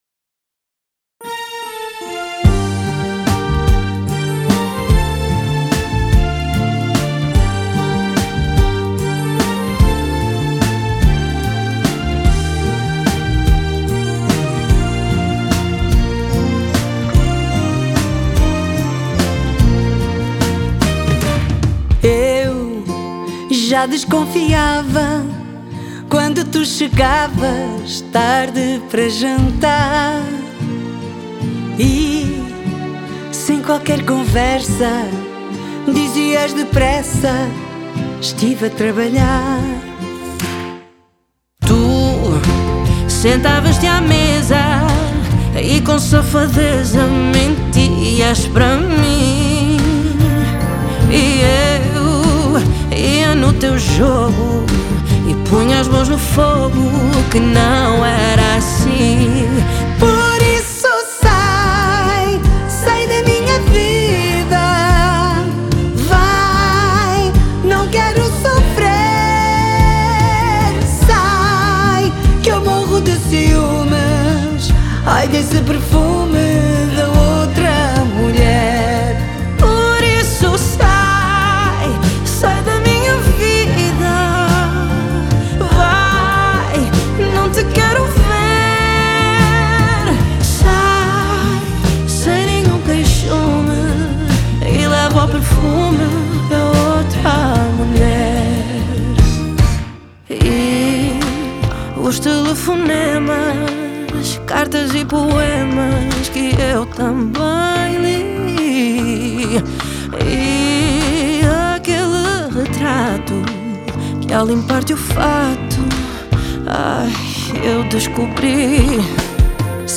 dueto
conhecida pela sua voz intensa e presença marcante